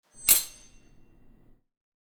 SWORD_28.wav